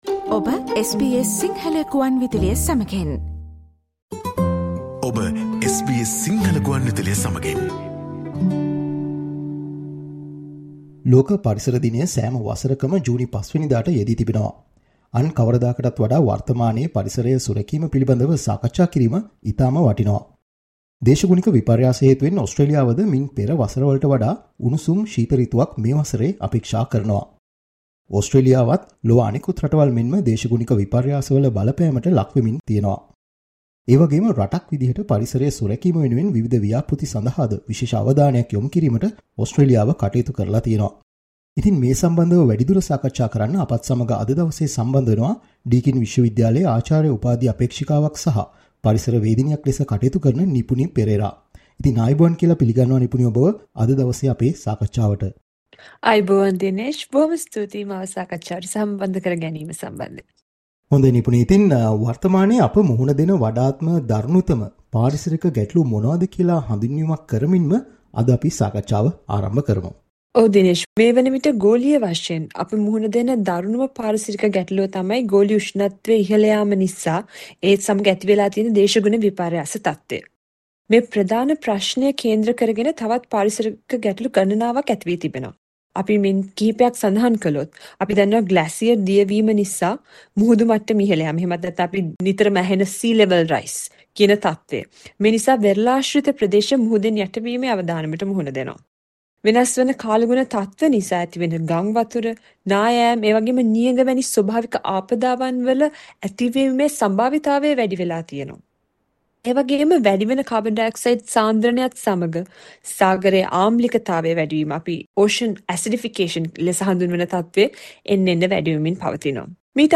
Australia, like the rest of the world, is being affected by climate change. Listen to SBS Sinhala interview for more information.